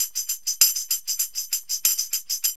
TAMB LP 98.wav